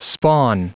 Prononciation du mot spawn en anglais (fichier audio)
Prononciation du mot : spawn